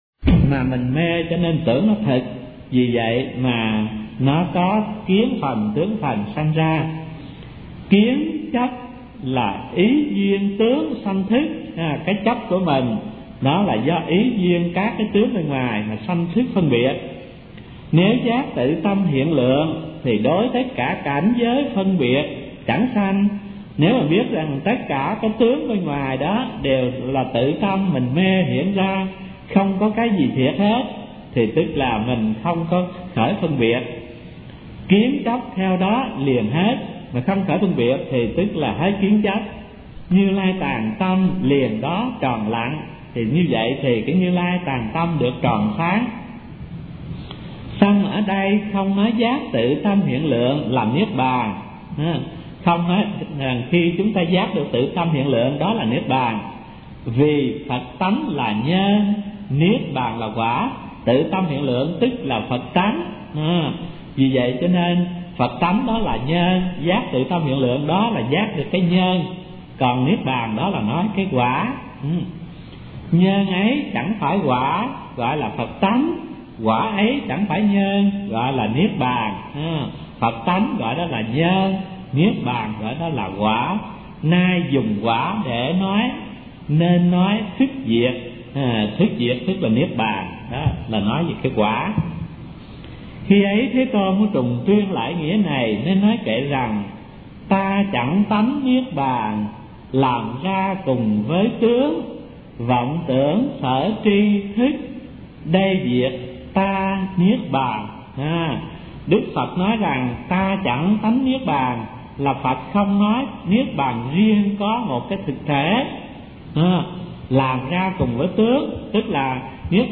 Kinh Giảng Kinh Lăng Già - Thích Thanh Từ